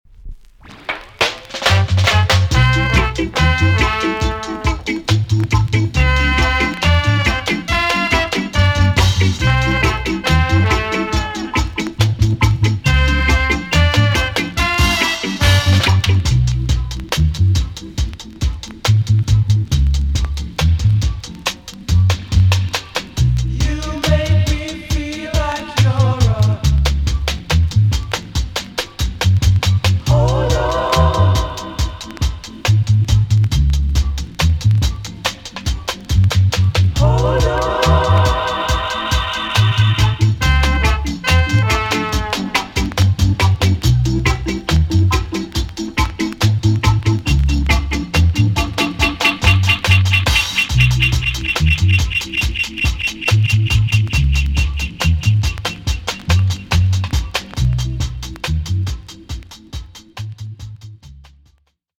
TOP >SKA & ROCKSTEADY
B.SIDE Version
VG+ 少し軽いチリノイズが入りますが良好です。